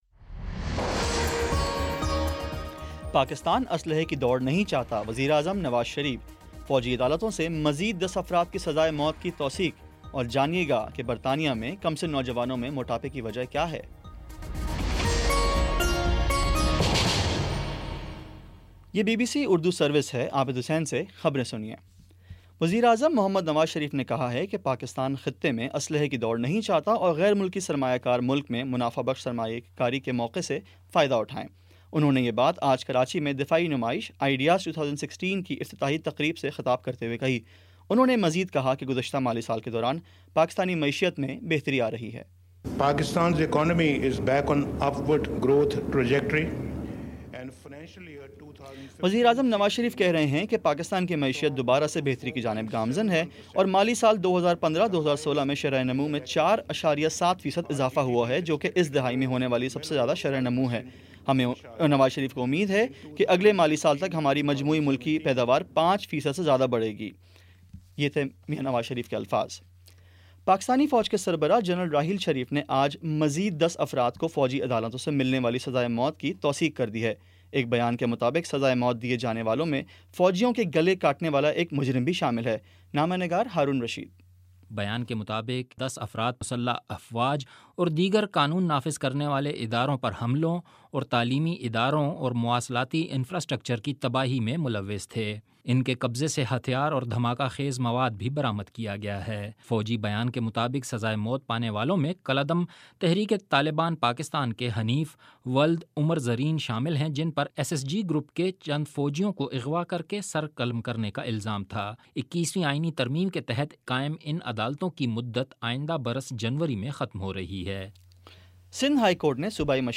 نومبر 22 : شام پانچ بجے کا نیوز بُلیٹن